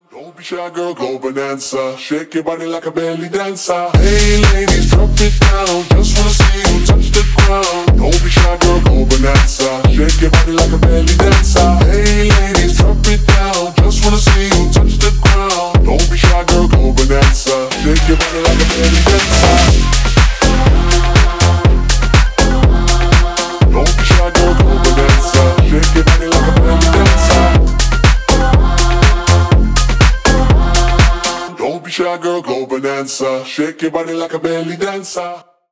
Заводной ремикс песенки из 2003 года
Dancehall Заводные Ремиксы Mashup Динамичные
Танцевальные